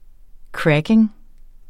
Udtale [ ˈkɹageŋ ]